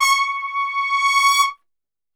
C#4 TRPSWL.wav